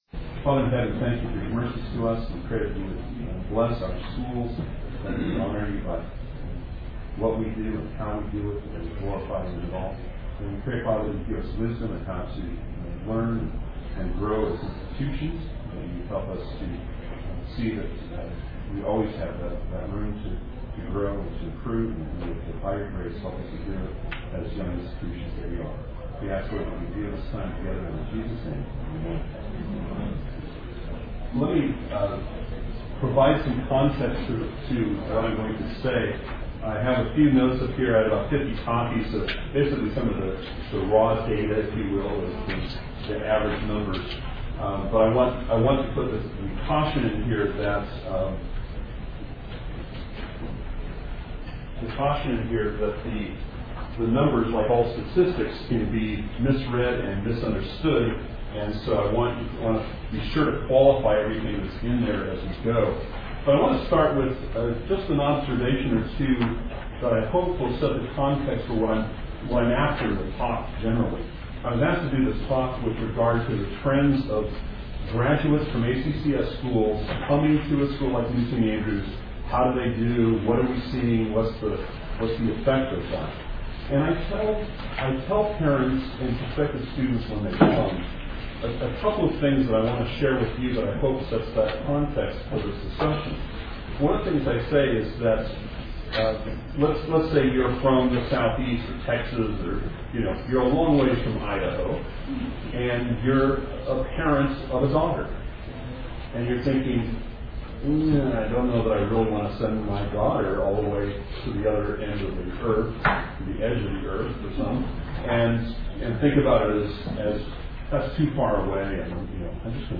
2008 Workshop Talk | 1:05:51 | Leadership & Strategic
Speaker Additional Materials The Association of Classical & Christian Schools presents Repairing the Ruins, the ACCS annual conference, copyright ACCS.